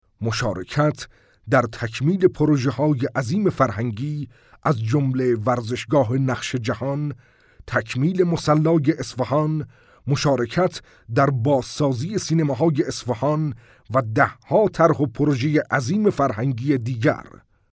Male
Senior
Commercial